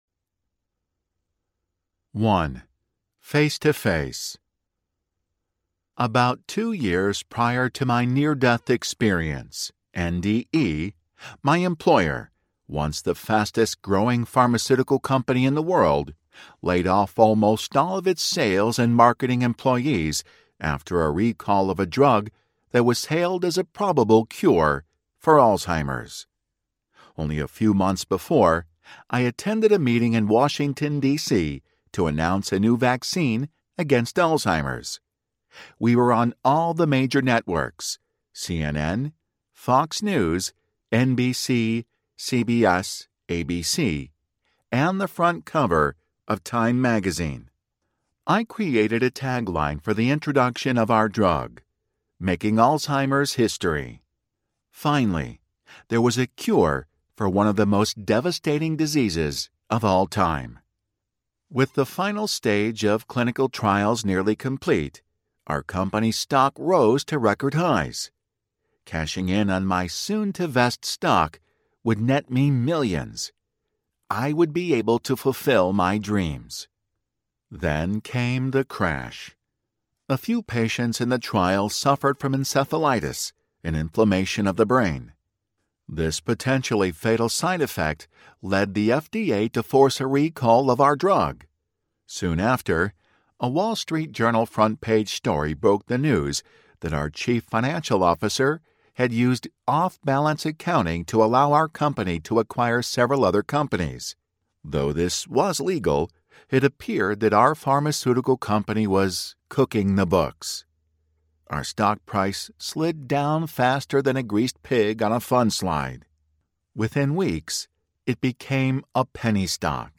Dying to Meet Jesus Audiobook
Narrator
6.3 Hrs. – Unabridged